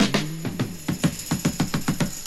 Chopped Fill 9.wav